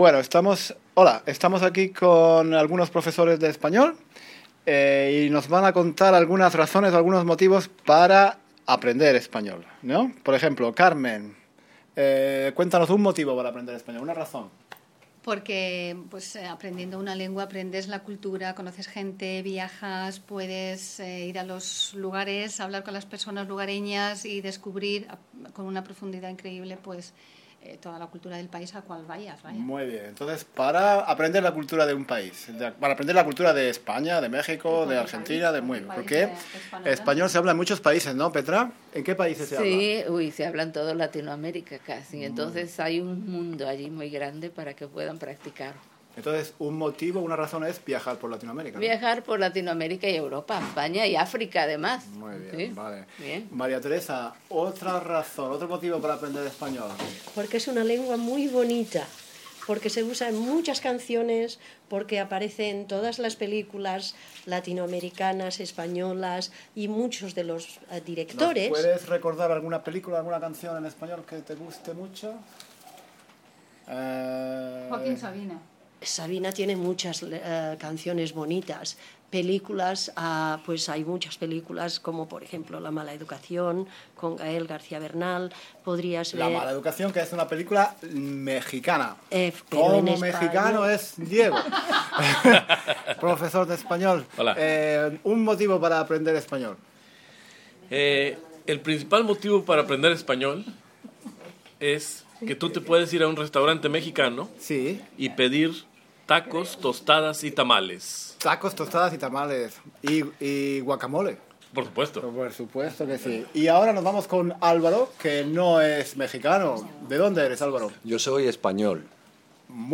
Algunos profesores nos dan razones para aprender español...